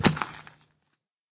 minecraft / sounds / mob / zombie / step1.ogg
step1.ogg